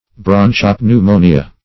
bronchopneumonia - definition of bronchopneumonia - synonyms, pronunciation, spelling from Free Dictionary
Broncho-pneumonia \Bron`cho-pneu*mo"ni*a\, n. [Bronchus +